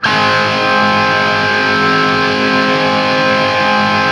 TRIAD G# L-R.wav